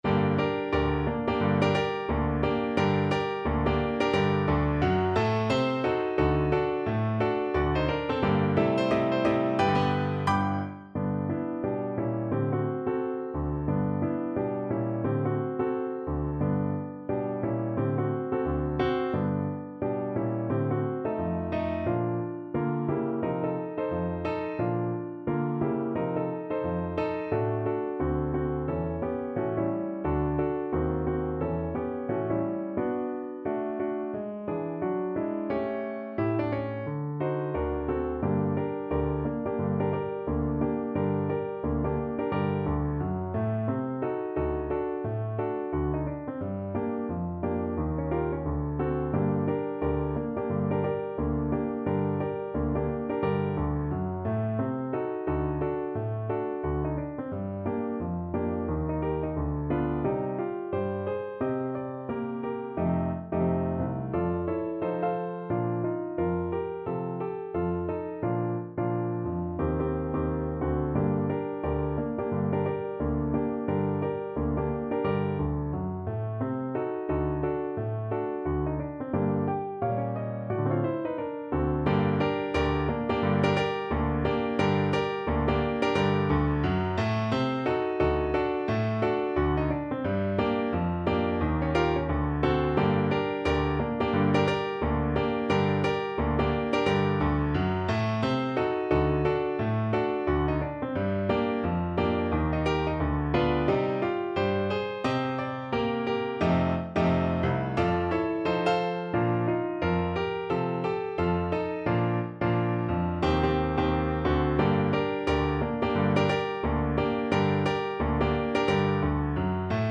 2/2 (View more 2/2 Music)
~ = 176 Moderato
Jazz (View more Jazz French Horn Music)